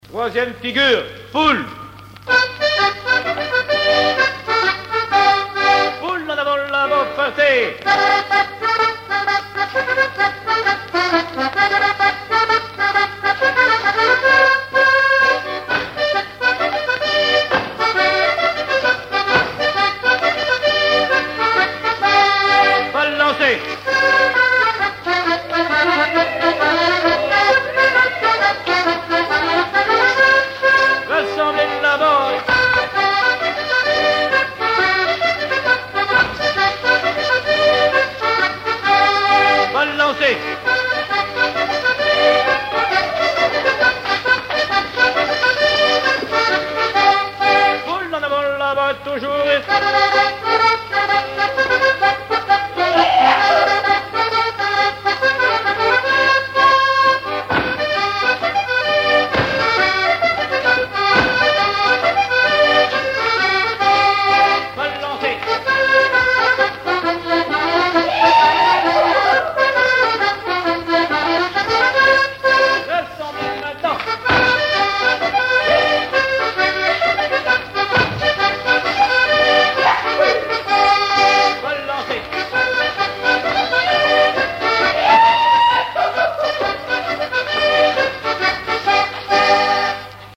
danse : quadrille : poule
Pièce musicale inédite